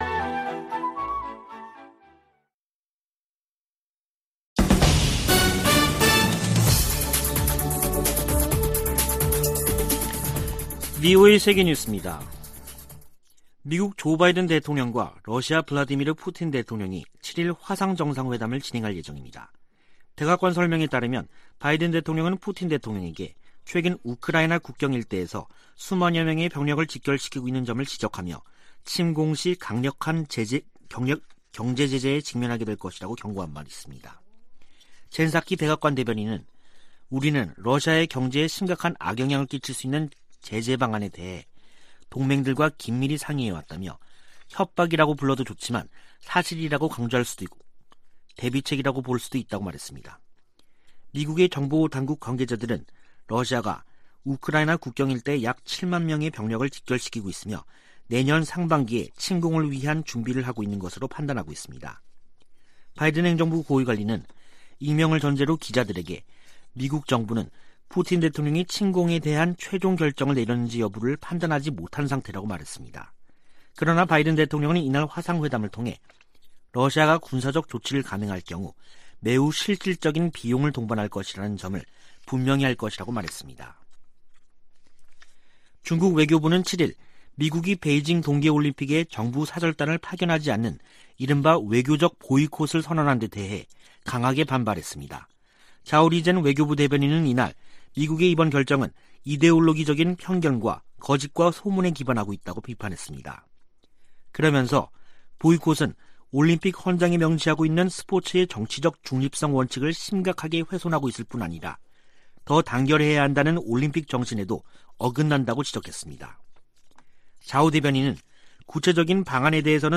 VOA 한국어 간판 뉴스 프로그램 '뉴스 투데이', 2021년 12월 7일 2부 방송입니다. 미국이 중국의 인권 탄압을 이유로 내년 2월 베이징 동계올림픽에 정부 공식대표단을 파견하지 않는다고 공식 발표했습니다. 유럽연합(EU)이 북한인 2명과 기관 1곳 등에 인권제재를 1년 연장했습니다. 조 바이든 미국 대통령이 오는 9일과 10일 한국과 일본 등 전 세계 110개국이 참가하는 민주주의 정상회의를 화상으로 개최합니다.